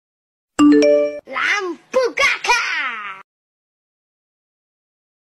Kategori: Nada dering
Suara imut